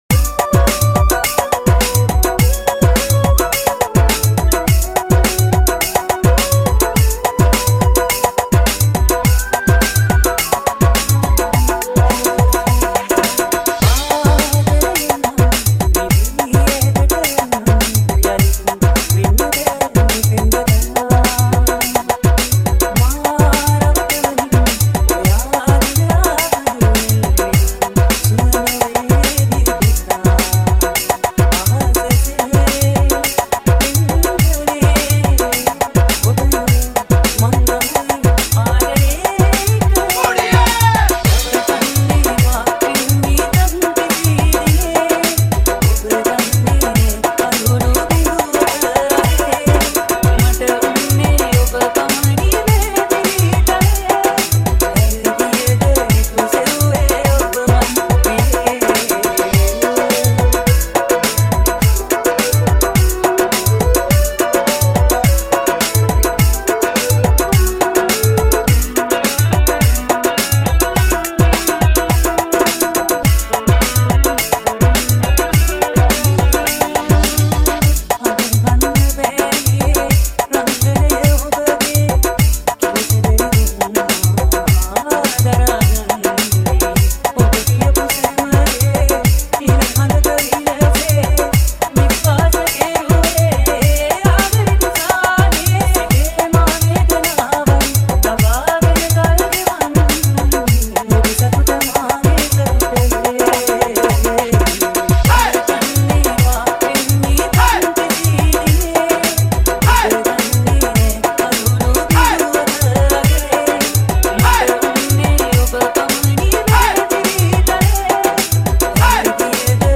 Sri Lankan remix